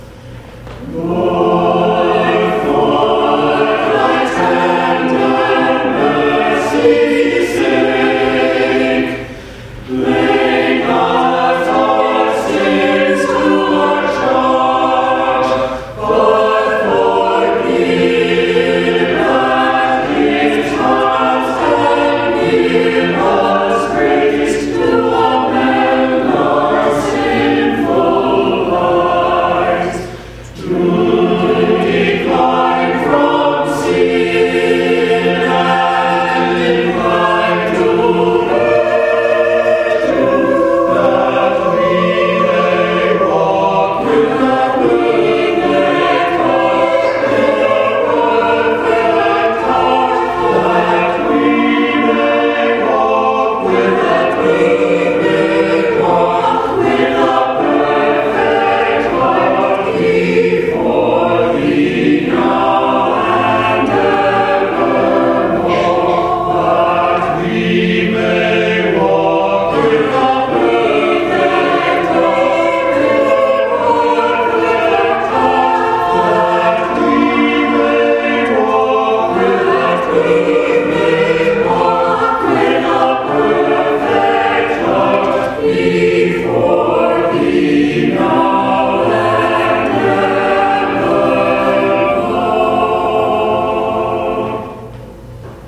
Lord, For Thy Tender Mercies Sake Richard Farrant MCC Senior Choir Anthem Sunday, November 9, 2014 Download file Lord, For Thy Tender Mercy’s Sake